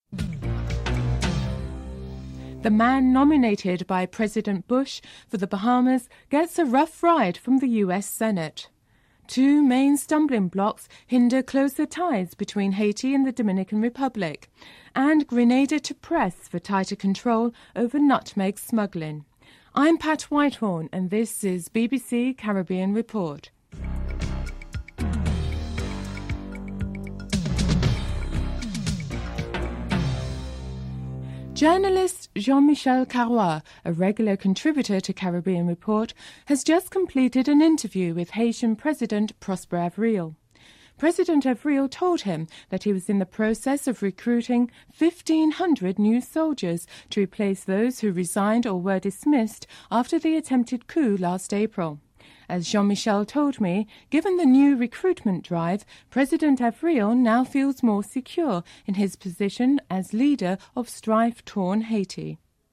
1. Headlines (00:00-00:23)
4. Financial news (06:46-08:40)